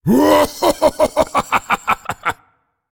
Risada Alistar (LoL)
Risada do minotauro Alistar de League Of Legends (LoL).
risada-alistar-lol.ogg